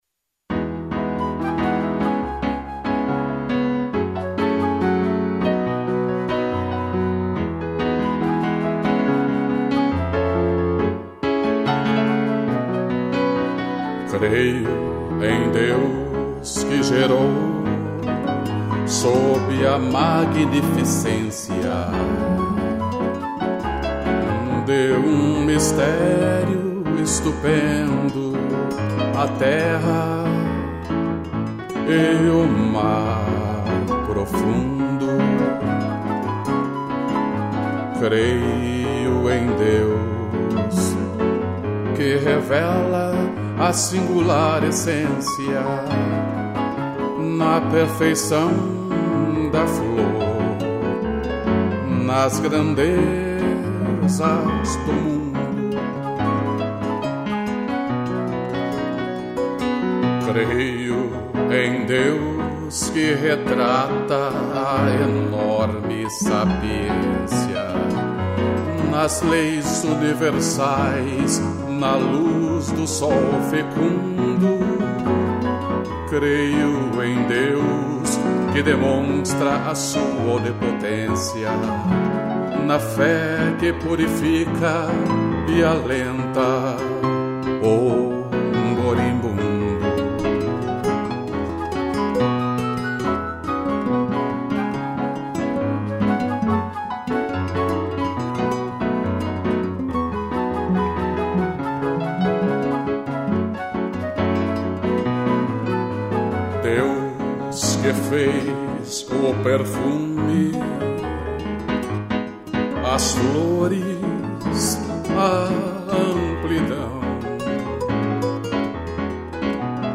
voz e violão
2 pianos e flauta